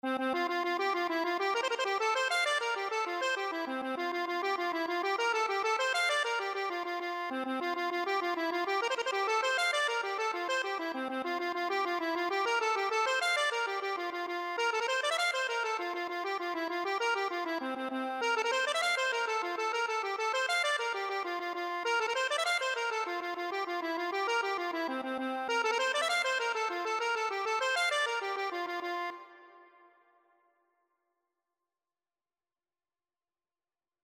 Traditional Trad. I Know What You Like (Irish Folk Song) Accordion version
Traditional Music of unknown author.
F major (Sounding Pitch) (View more F major Music for Accordion )
6/8 (View more 6/8 Music)
C5-F6